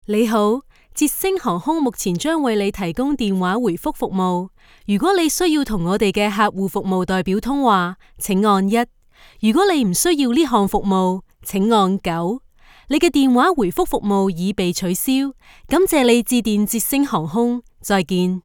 Never any Artificial Voices used, unlike other sites. All our voice actors are premium seasoned professionals.
On Hold, Professional Voicemail, Phone Greetings & Interactive Voice Overs
Adult (30-50) | Yng Adult (18-29)